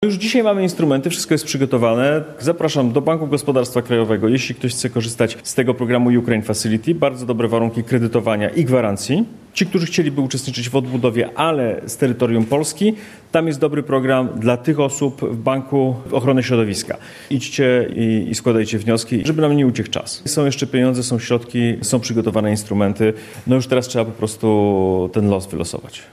– wyjaśnia poseł Platformy Obywatelskiej.